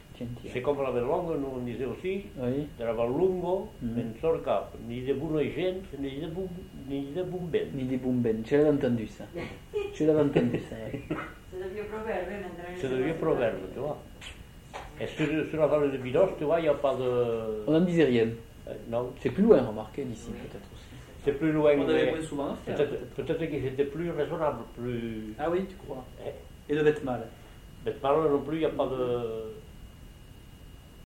Genre : forme brève
Effectif : 1
Type de voix : voix d'homme
Production du son : récité